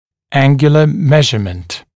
[‘æŋgjulə ‘meʒəmənt][‘энгйулэ ‘мэжэмэнт]угловое измерение, измерение величины угла